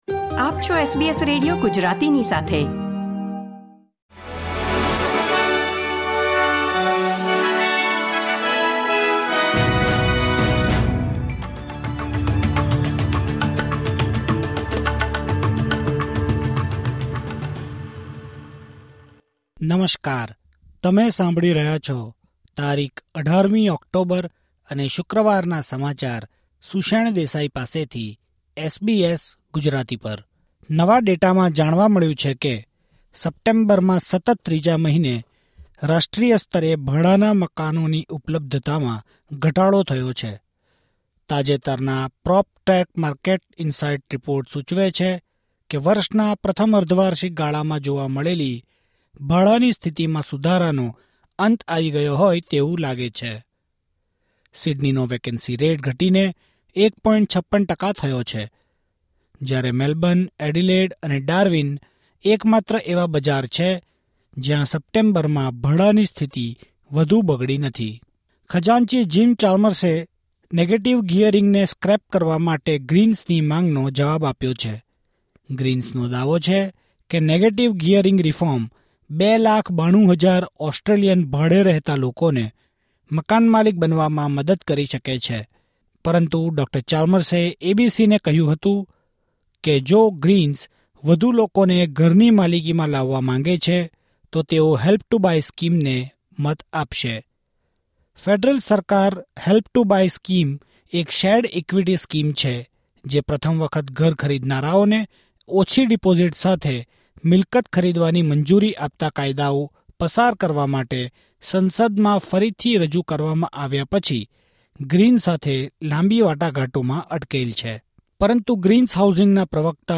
SBS Gujarati News Bulletin 18 October 2024